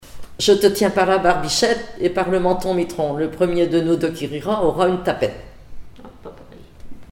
formulette enfantine
Comptines et formulettes enfantines
Pièce musicale inédite